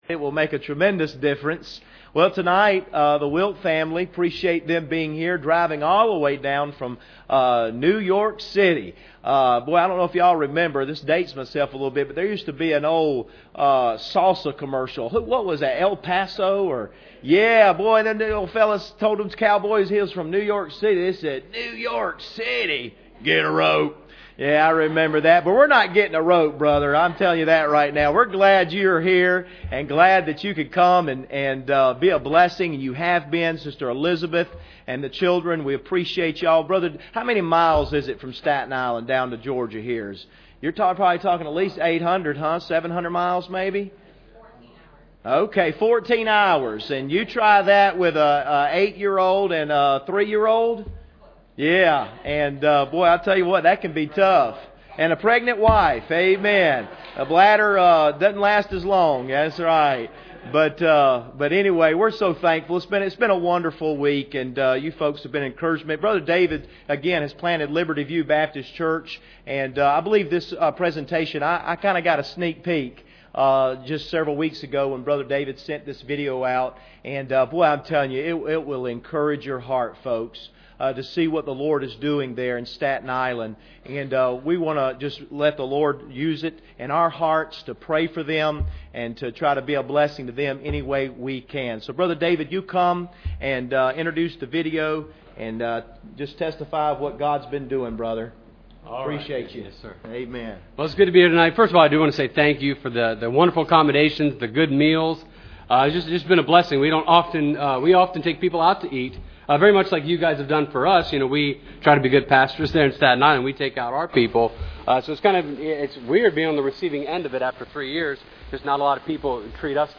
Service Type: Special Service